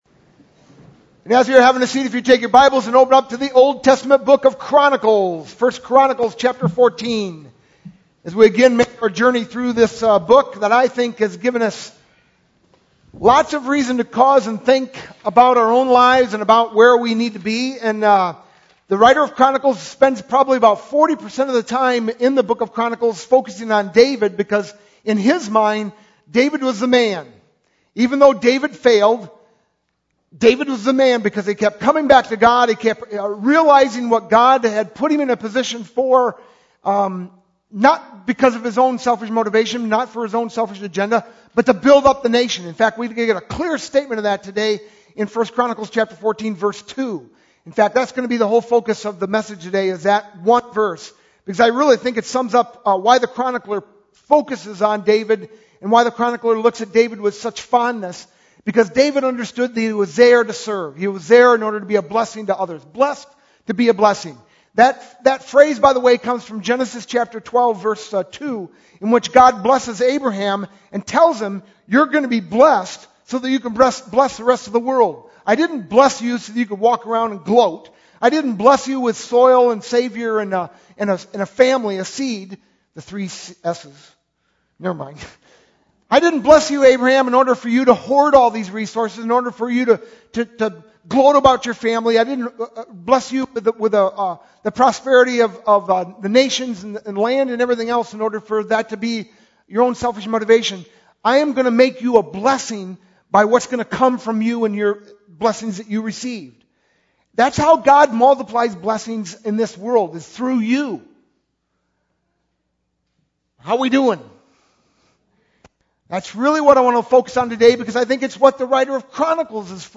sermon-5-20-12.mp3